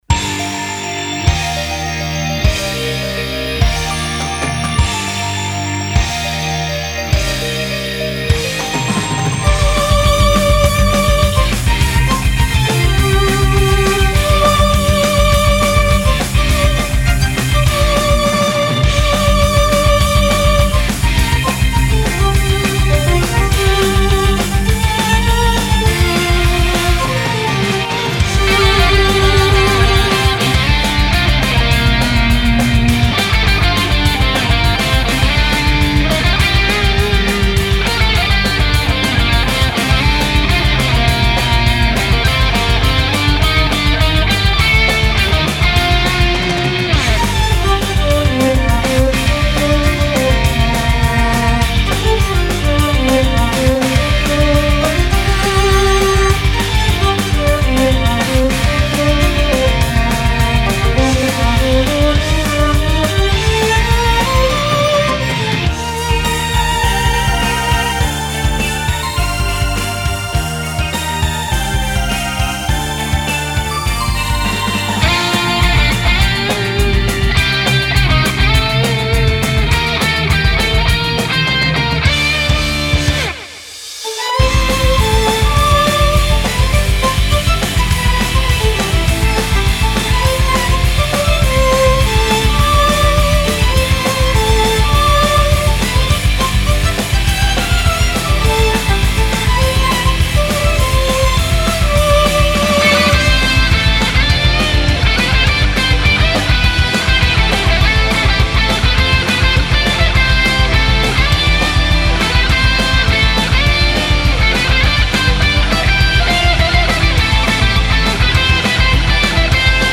フリーBGM バトル・戦闘 バンドサウンド